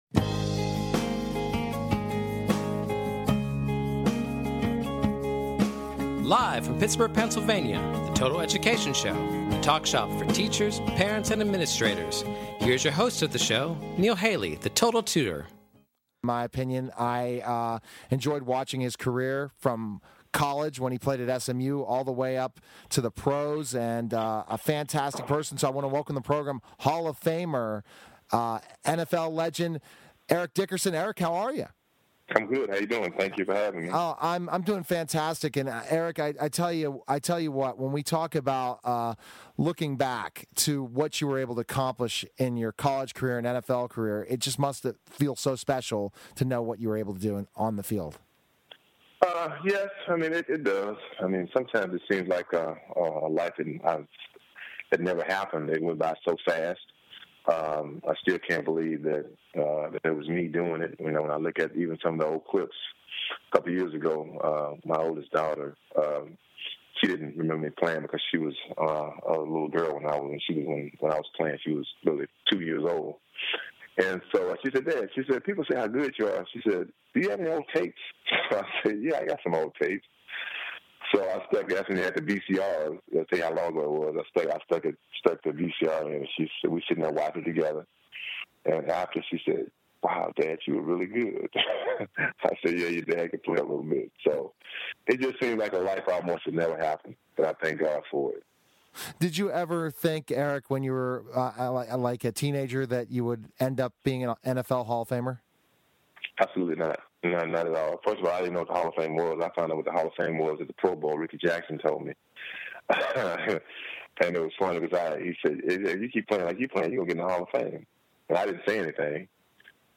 Talk Show Episode, Audio Podcast, Total_Education_Show and Courtesy of BBS Radio on , show guests , about , categorized as